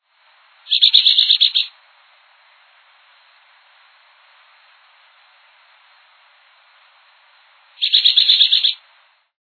Spezies: Euphonia pectoralis
Deutsch: Braunbauch-Organist
Vogelruf: